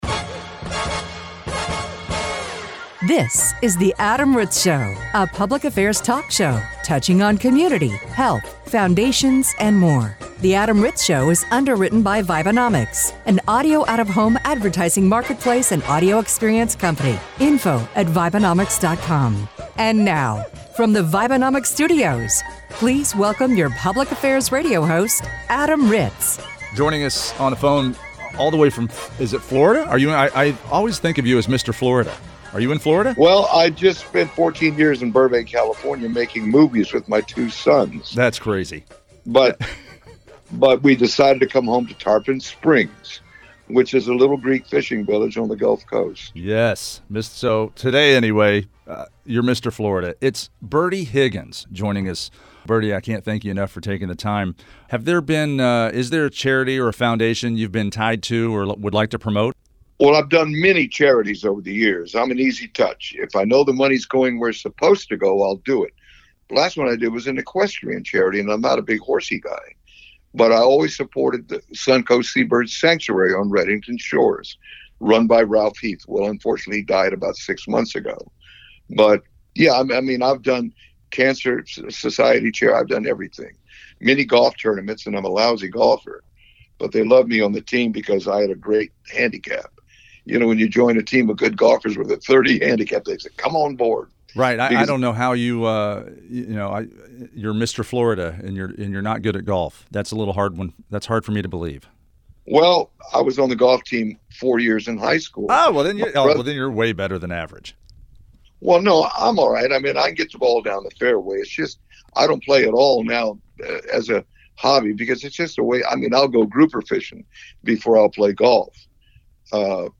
This show features a conversation with Bertie Higgins.